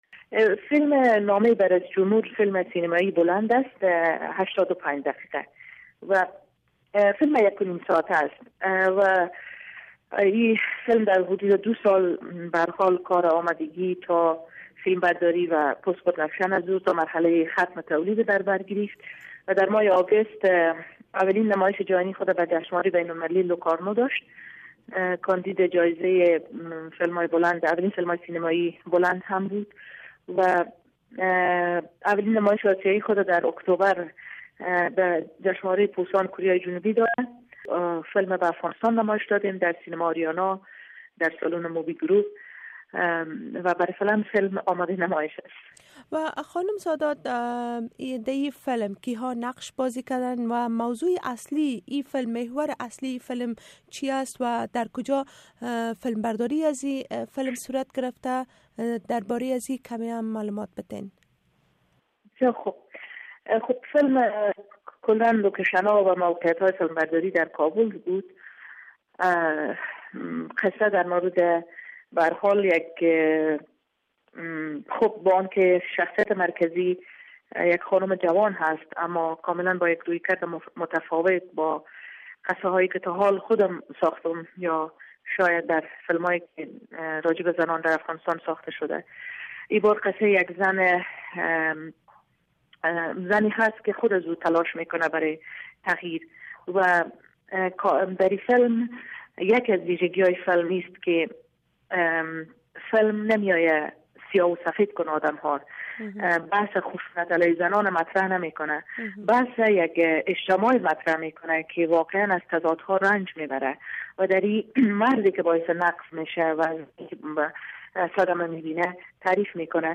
شرح مصاحبه با رویا سادات را در اینجا بشنوید: